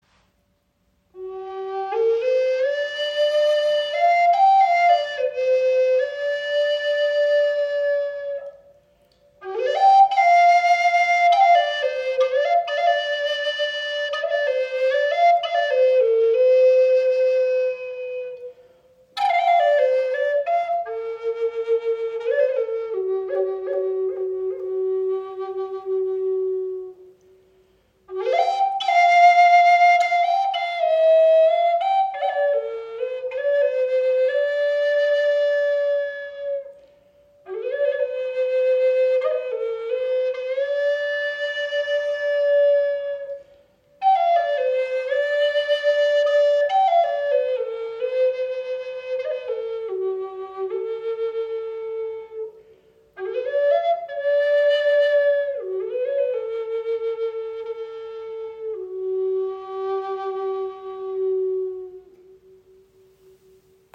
Klangbeispiel
Diese besondere Gebetsflöte ist in G gestimmt und wurde aus Walnuss erschaffen.
Zudem wurde das Holz durch Öle versiegelt, was den warmen Klang der Flöte unterstützt.